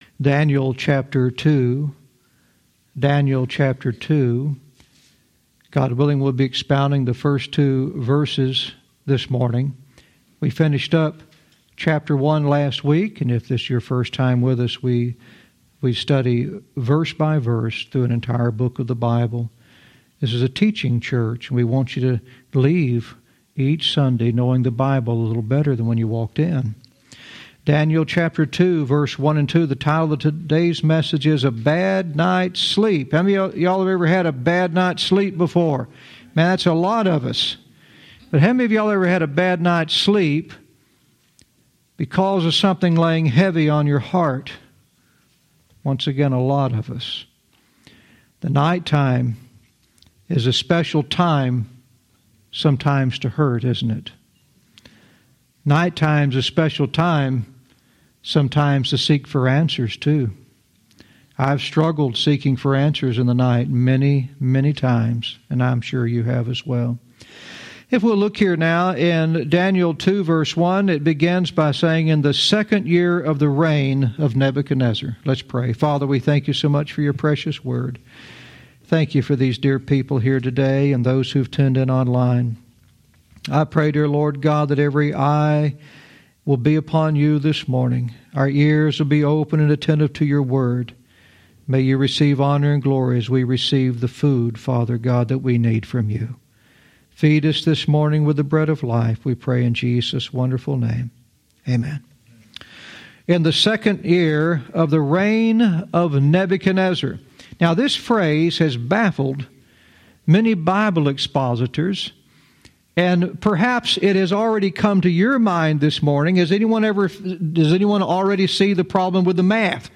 Verse by verse teaching - Daniel 2:1-2 "A Bad Night's Sleep"